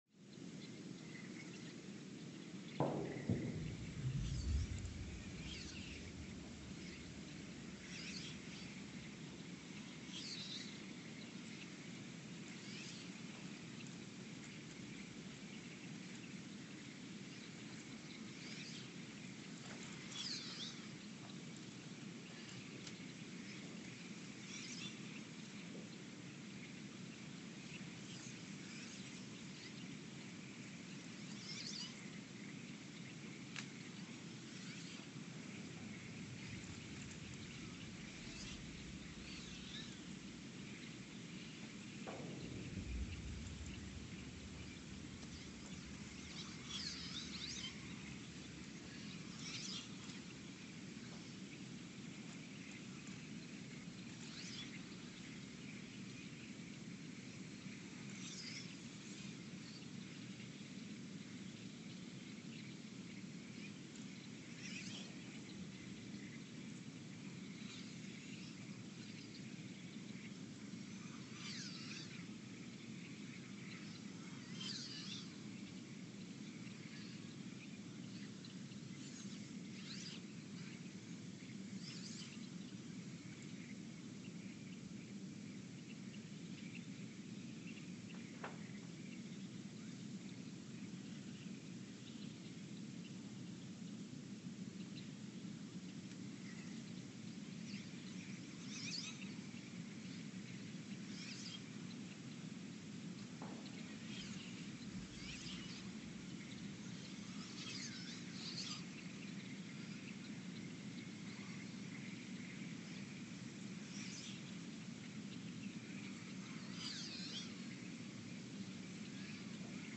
The Earthsound Project is an ongoing audio and conceptual experiment to bring the deep seismic and atmospheric sounds of the planet into conscious awareness.
Speedup : ×900 (transposed up about 10 octaves)
Loop duration (audio) : 11:12 (stereo)